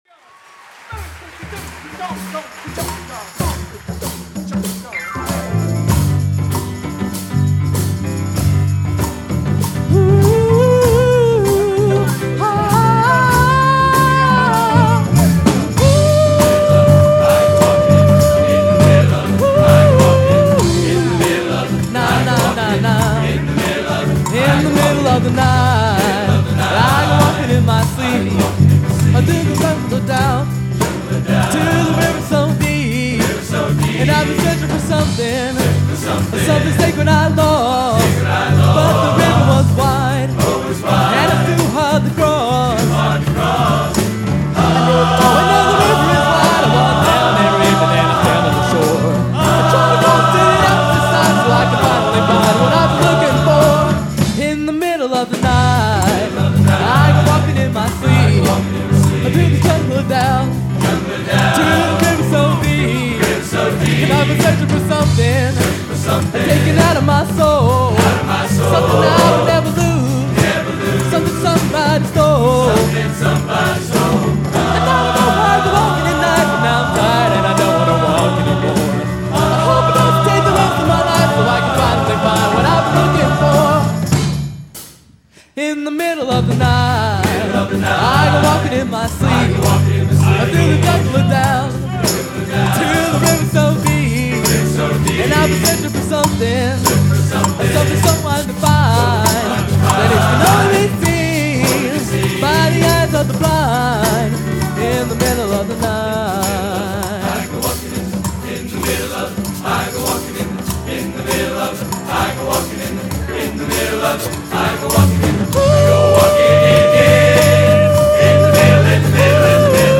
Location: Northrop High School, Fort Wayne, Indiana
Genre: Popular / Standards | Type: